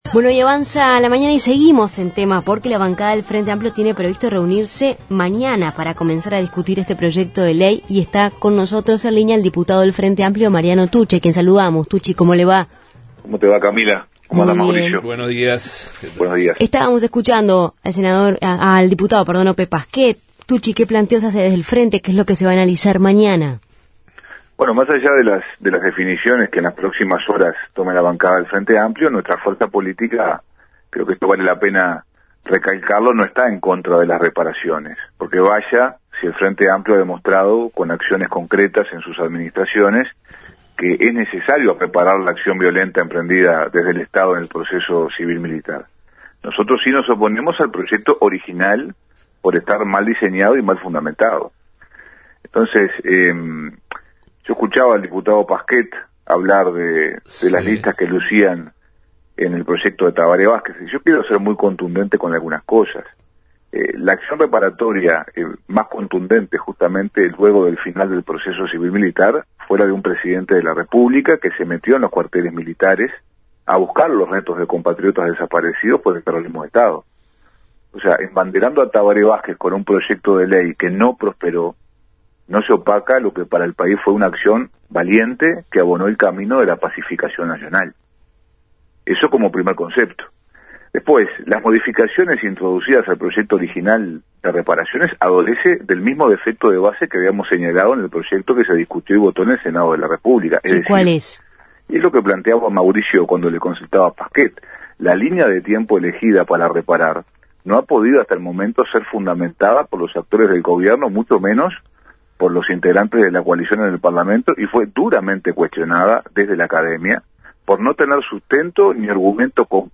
En Justos y pecadores entrevistamos al diputado colorado Ope Pasquet y al diputado frenteamplista Mariano Tucci, sobre el proyecto de Ley de reparación a víctimas de la guerrilla